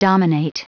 Prononciation du mot dominate en anglais (fichier audio)
Prononciation du mot : dominate